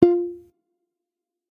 receive-message.oga